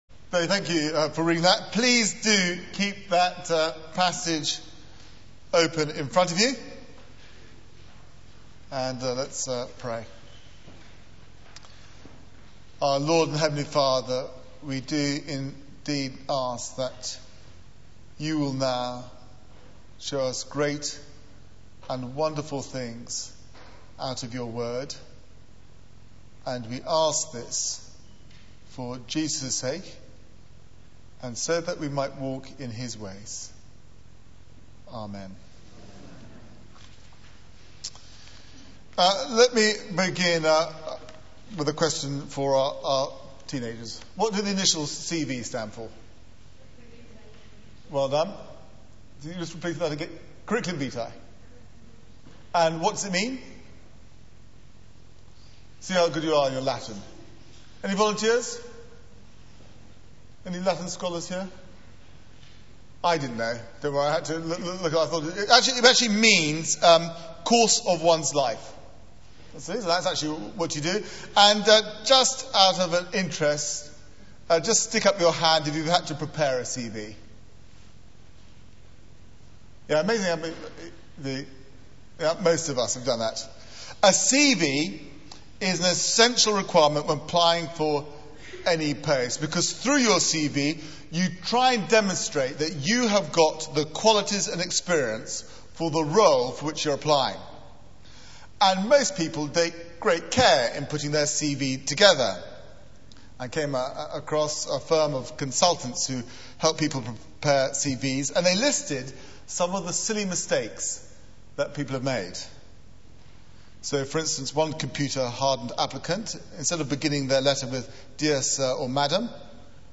Media for 9:15am Service on Sun 09th Nov 2008 18:30 Speaker: Passage: 2 Cor 11:16-33 Series: Meekness is Strength Theme: The Fool's Speech Sermon slides Open Search the media library There are recordings here going back several years.